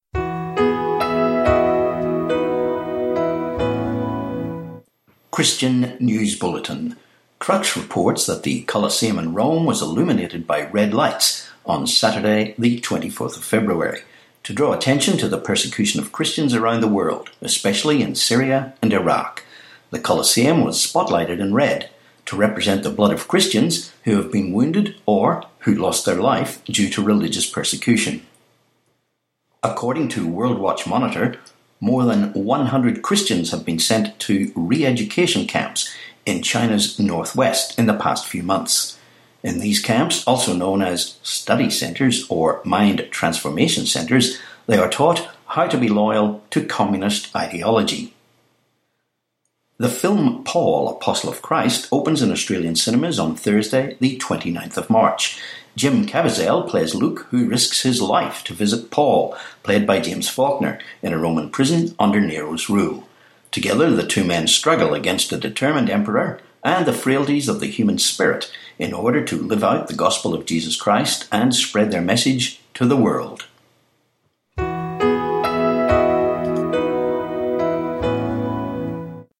25Feb18 Christian News Bulletin